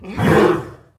CosmicRageSounds / ogg / general / combat / creatures / horse / he / attack2.ogg
attack2.ogg